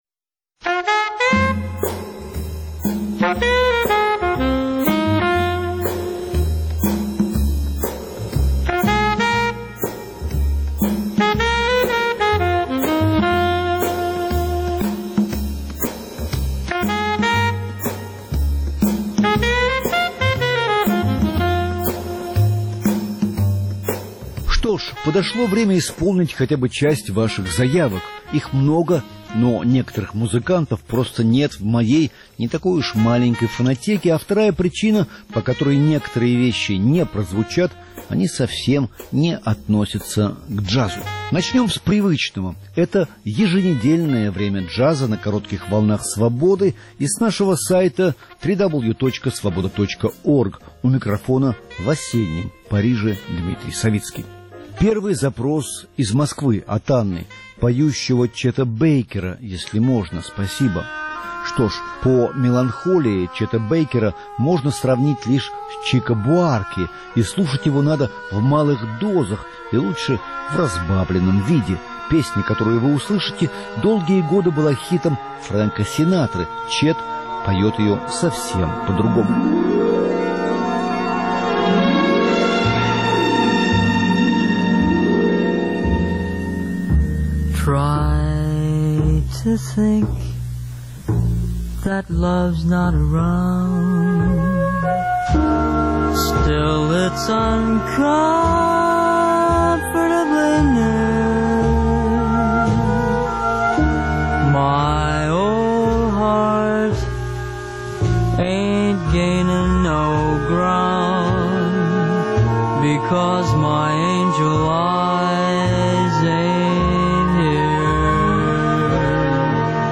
Все жанры, все эпохи.